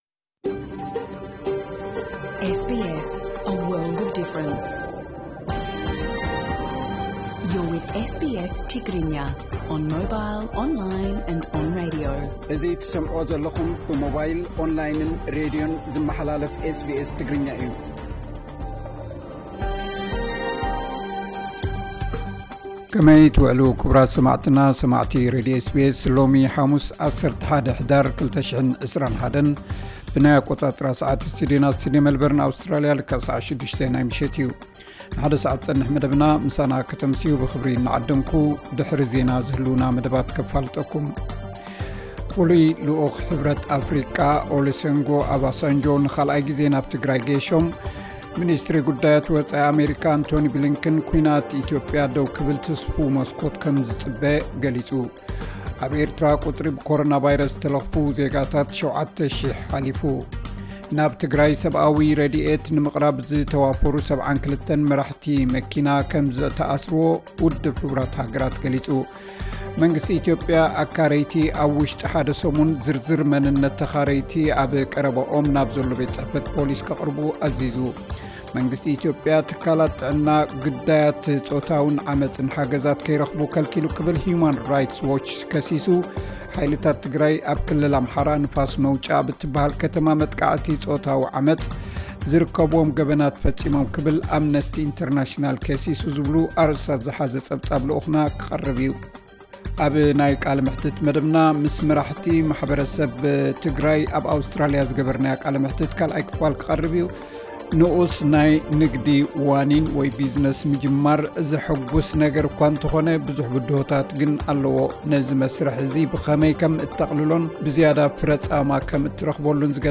ዕለታዊ ዜና SBS ትግርኛ (11 ሕዳር 2021)